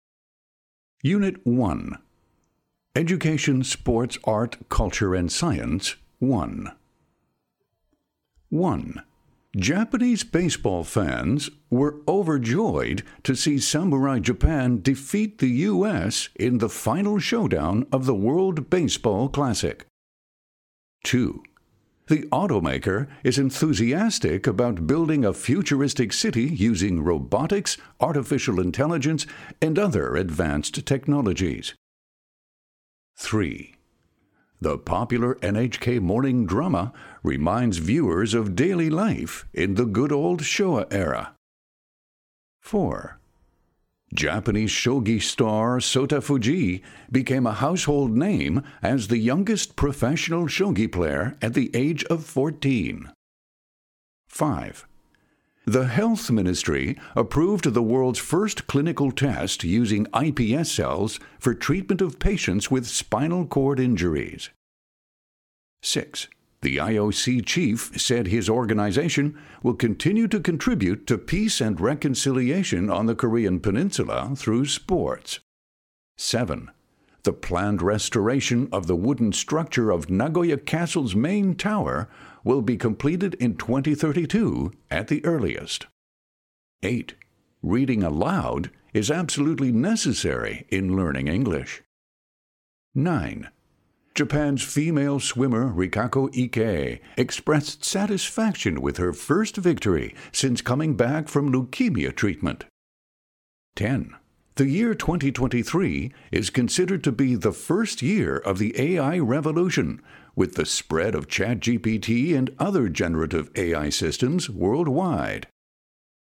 ニュース英語をベースに、自然なスピードとイントネーションでリスニング力を鍛えるとともに、英語報道で頻出する「新4000語」を効率的に学習できる構成です。
リスニングの徹底強化：ラジオニュース形式の音声データで、自然な英語に耳を慣らします。